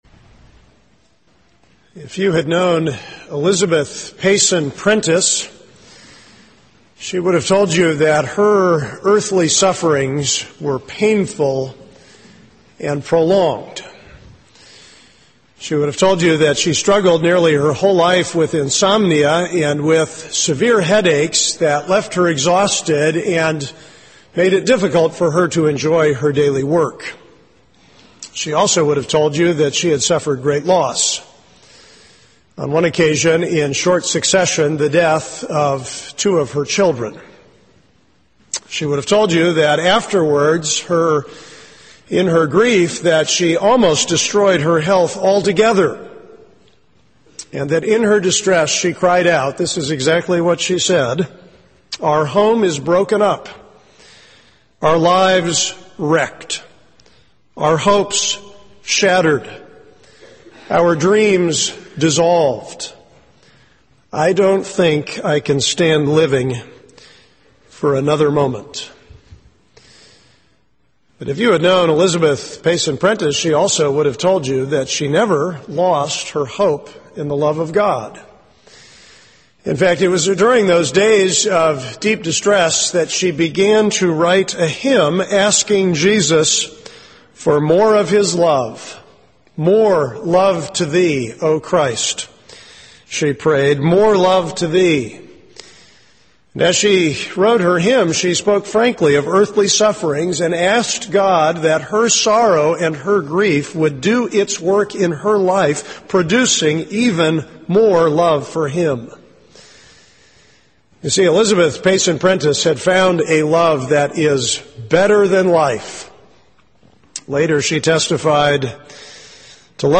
Download Audio Category Sermon Love that is Better Than Life Series